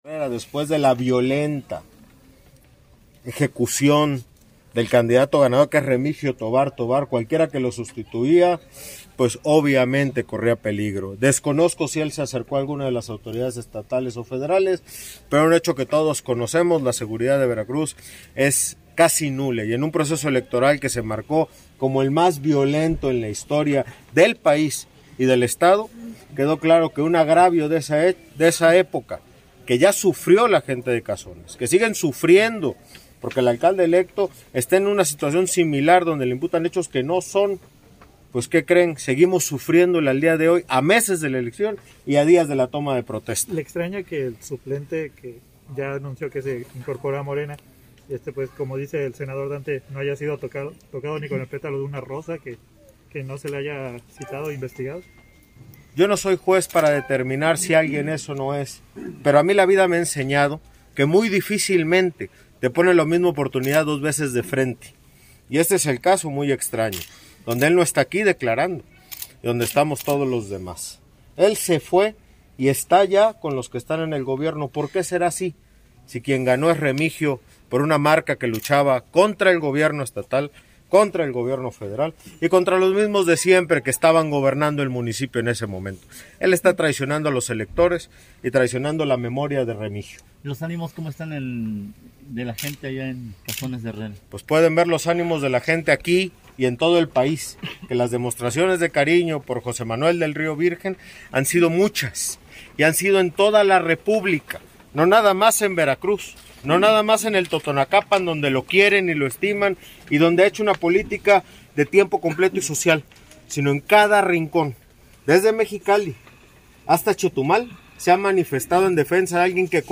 Entrevistado en las afueras del penal de Pacho Viejo, explicó que se lleva a cabo una audiencia tardada, «entenderán que en esta nueva versión de los juicios orales se da mucho la réplica y contra réplica… han estado centrados en lo mismo, dando y dando la vuelta sobre qué asume y quién asume».